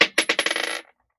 Bouncing Bullet 001.wav